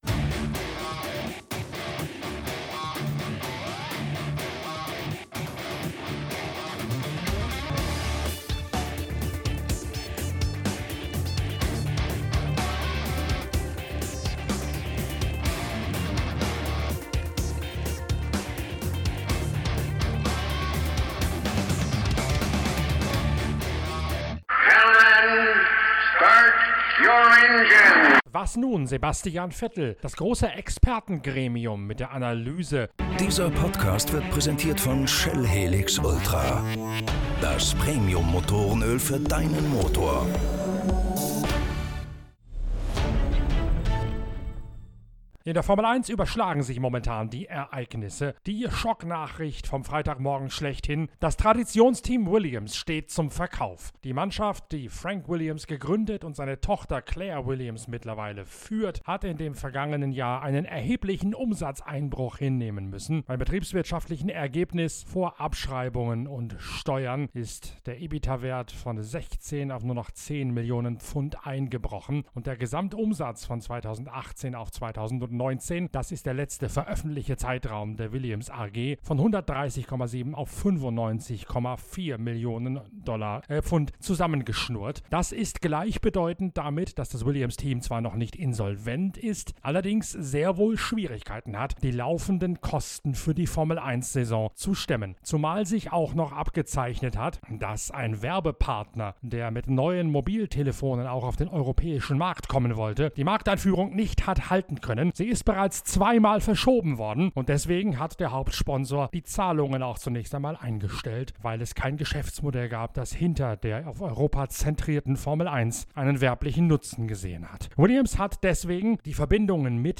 Vor der Gesprächsrunde gibt es einen Überblick über die neuesten Entwicklungen in der Formel 1.